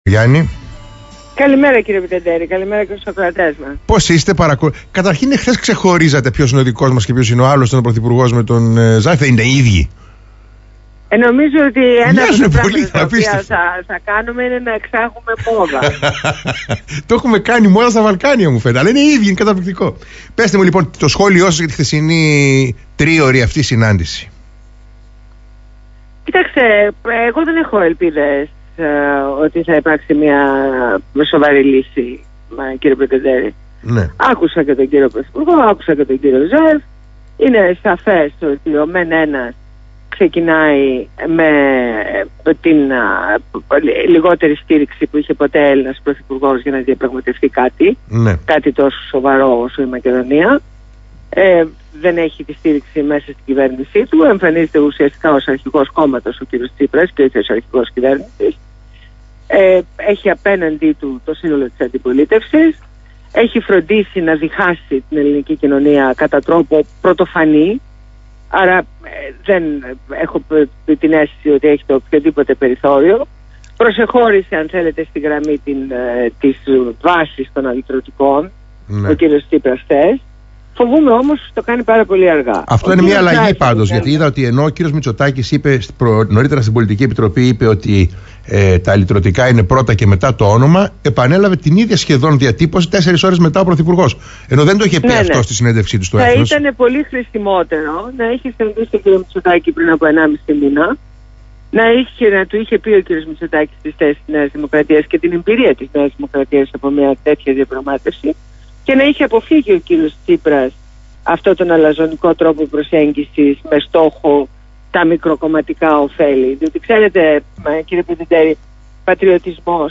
Συνέντευξη στο ραδίοφωνο ΘΕΜΑ radio, στο δημοσιογράφο Γ. Πρετεντέρη.